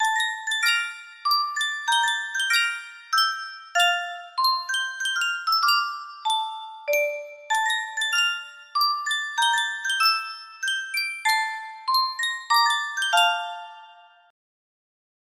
Superstar Music Box - Schubert's Serenade I5 music box melody
Full range 60